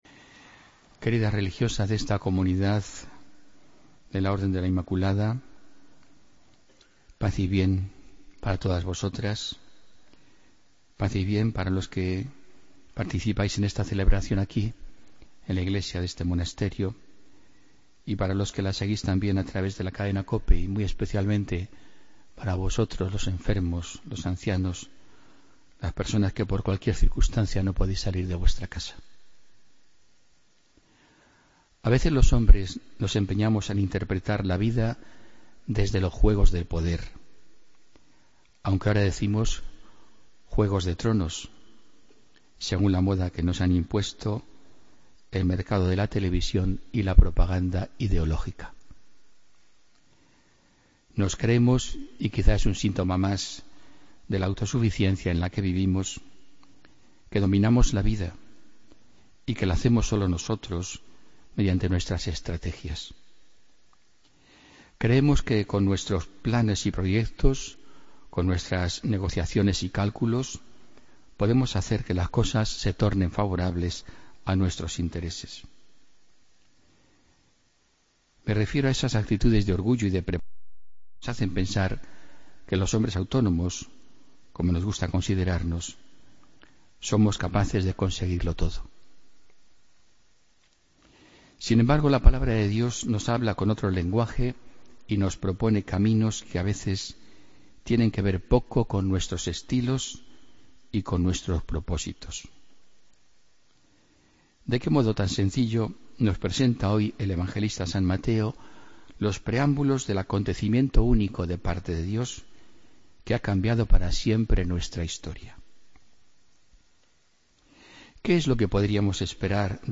Homilía del domingo 18 de diciembre de 2016